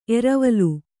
♪ eravalu